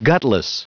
Prononciation du mot gutless en anglais (fichier audio)